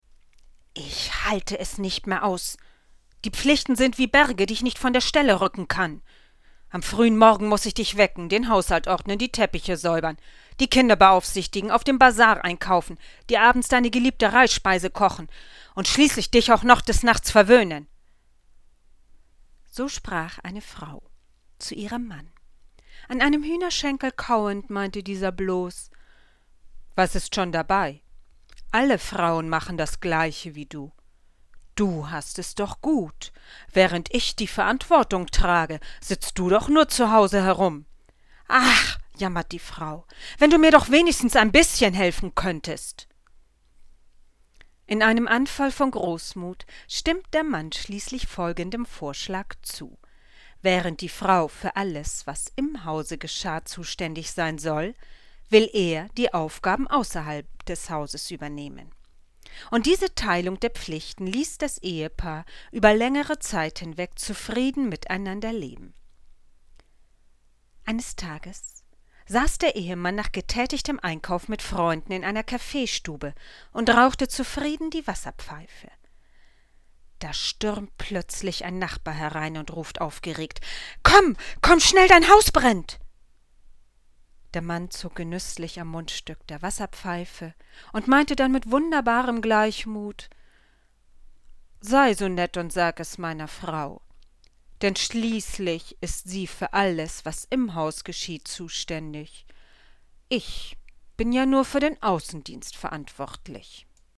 - Etwas Nettes für die Ohren - Hier finden Sie Sprechproben.